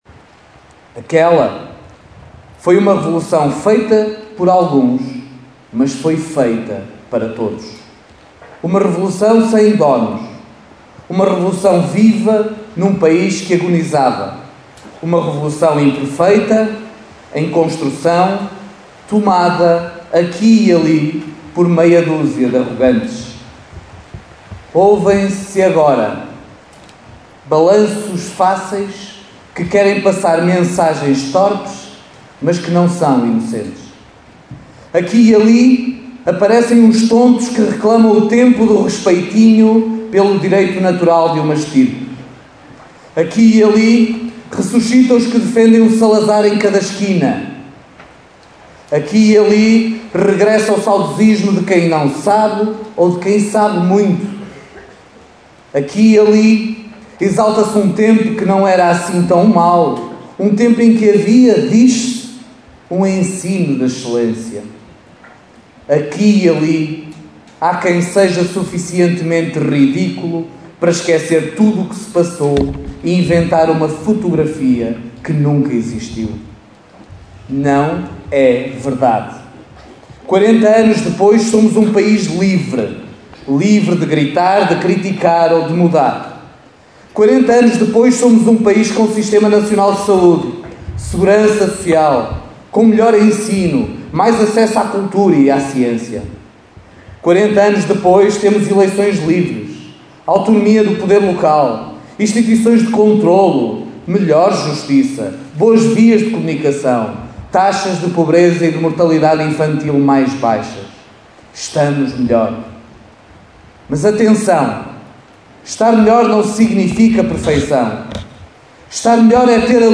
Foi com chuva que o 25 de Abril foi celebrado esta manhã em Caminha.